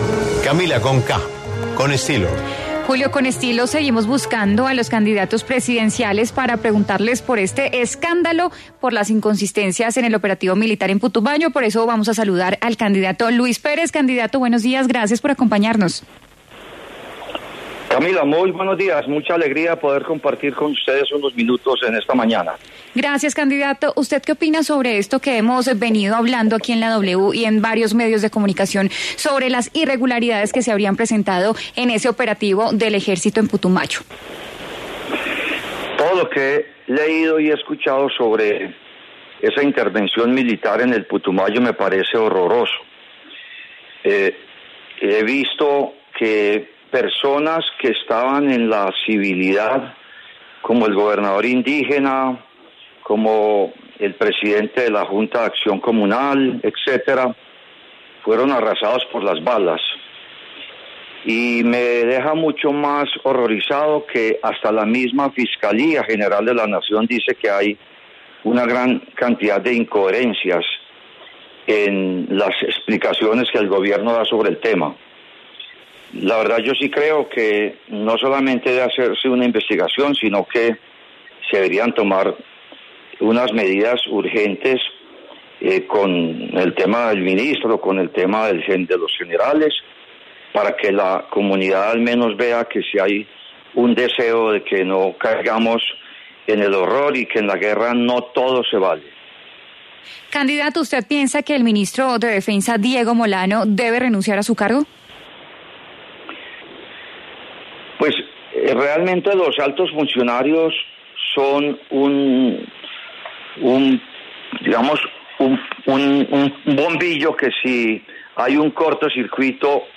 Sonó en La W: